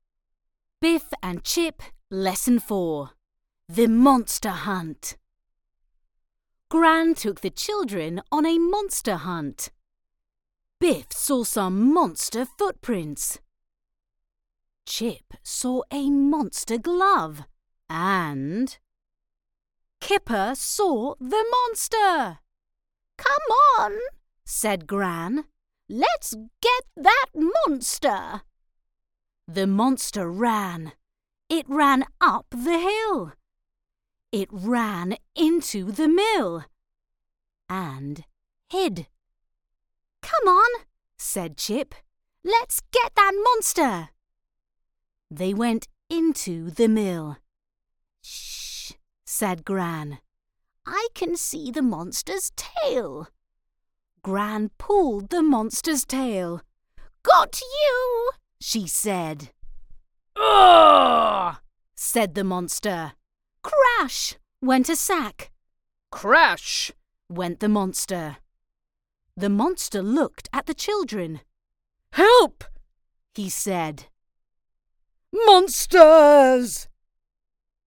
Australian Female Voice Over Artists, Talent & Actors
English (Scottish)
English (Australian)
Adult (30-50) | Yng Adult (18-29)